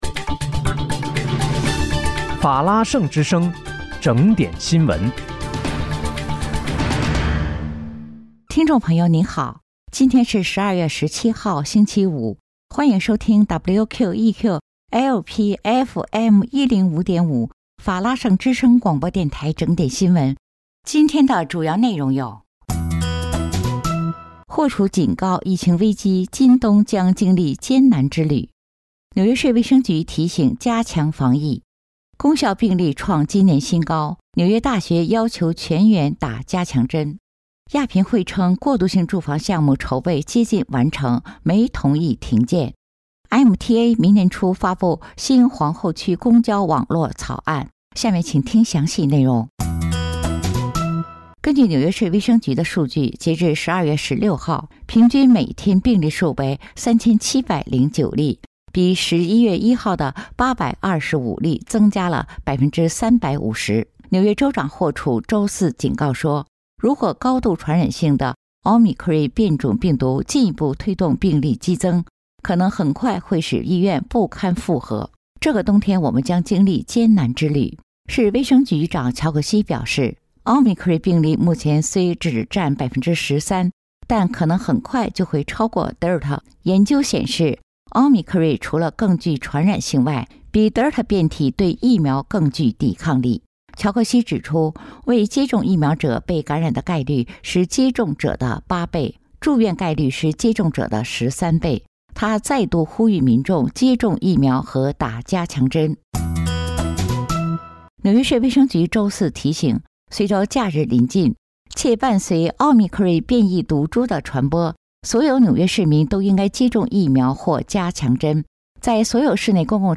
12月17日（星期五）纽约整点新闻